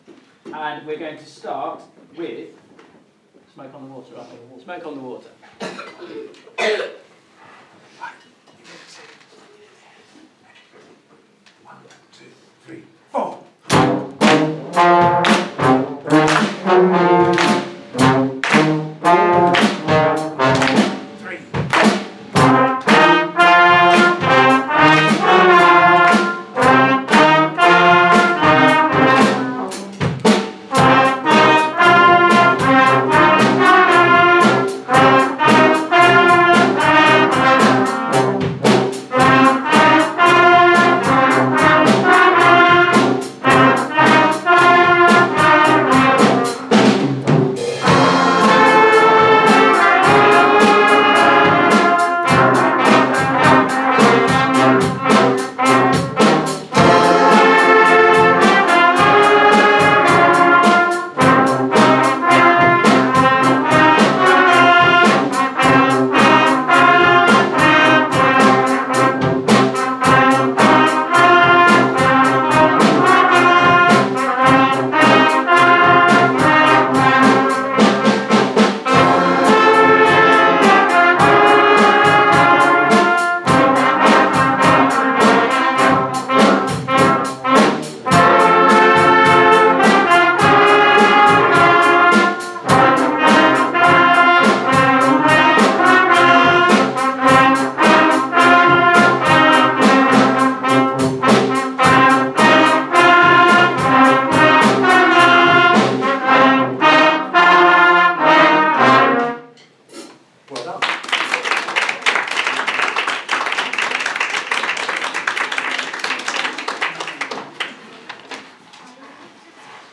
Junior Brass March 2018